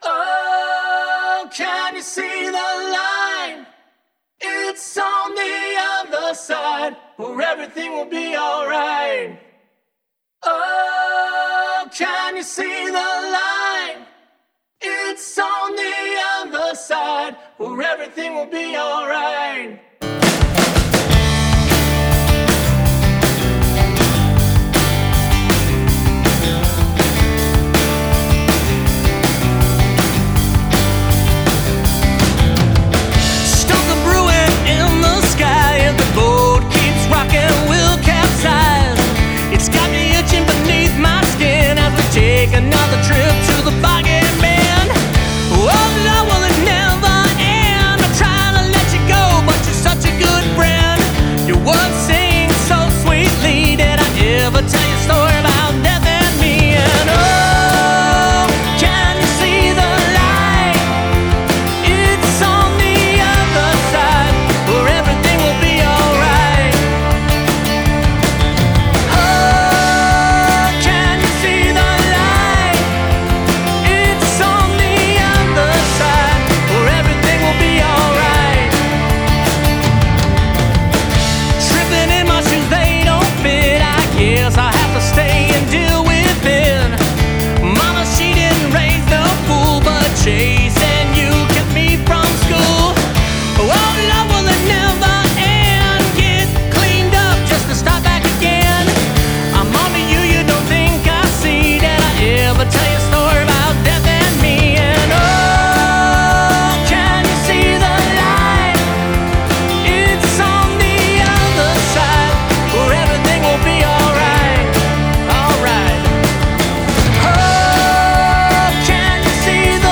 Through delicate guitar arrangements and heartfelt lyrics